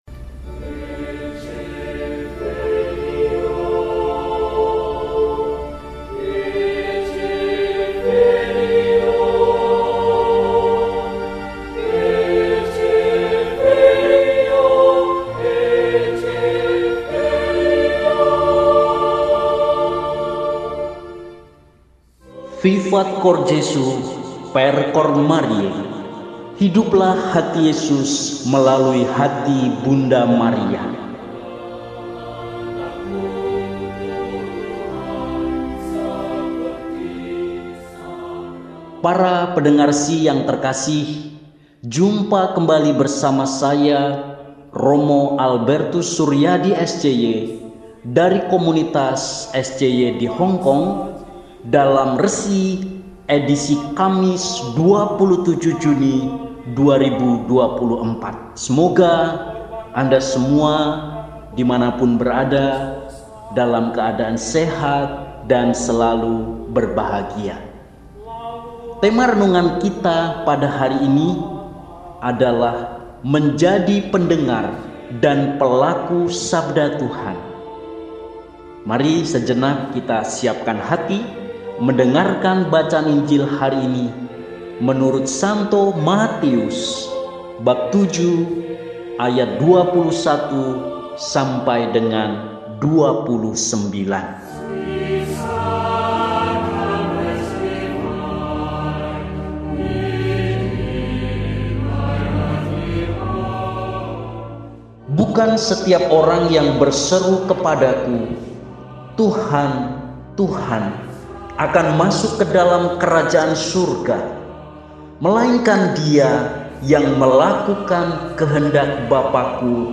Kamis, 27 Juni 2024 – Hari Biasa Pekan XII – RESI (Renungan Singkat) DEHONIAN